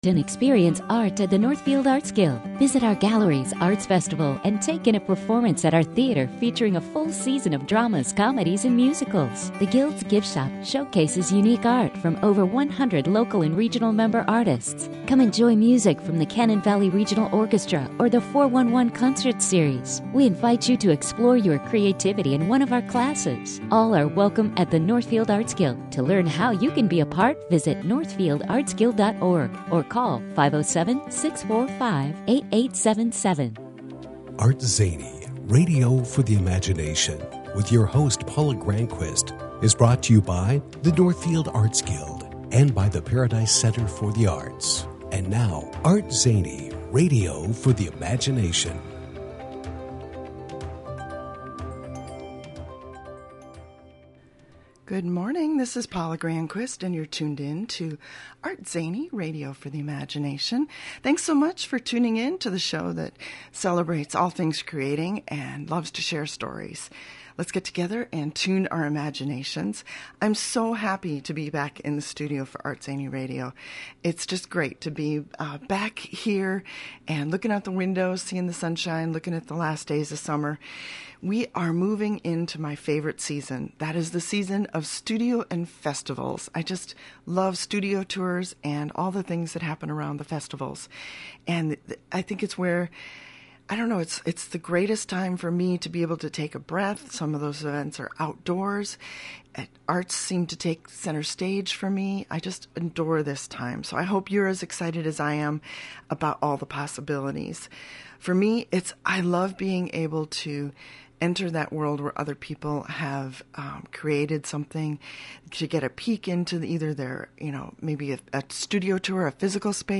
Today in the ArtZany Radio studio